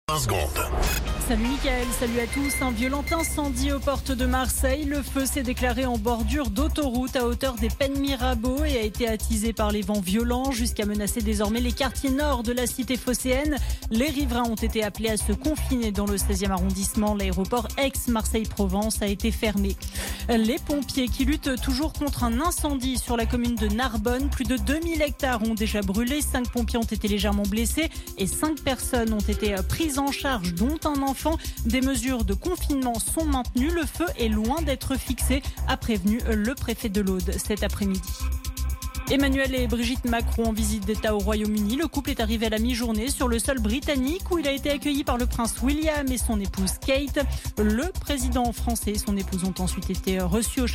Flash Info National 08 Juillet 2025 Du 08/07/2025 à 16h10 .